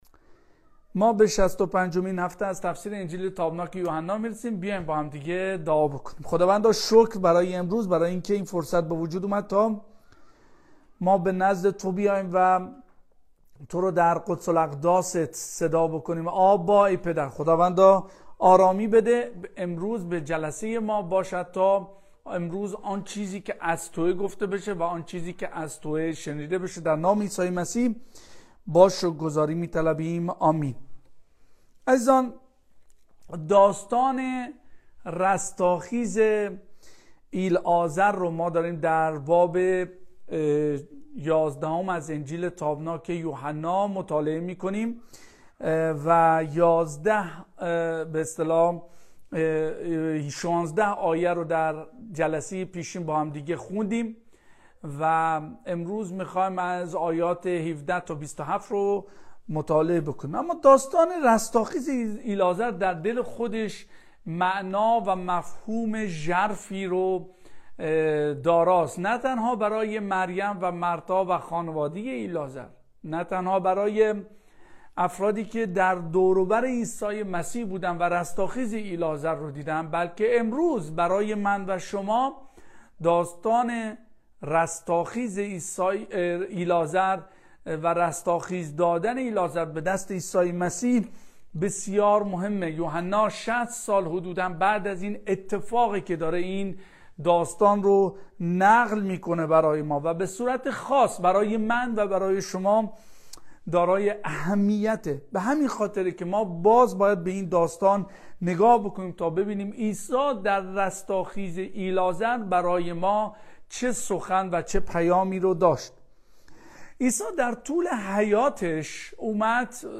تفسیر و موعظه تشریحی انجیل یوحنا هفته ۶۵ | ۱۷:۱۱-۲۷